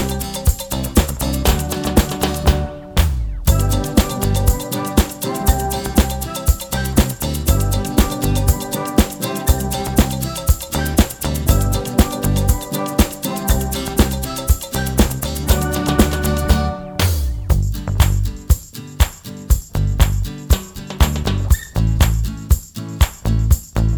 no Backing Vocals Glam Rock 3:16 Buy £1.50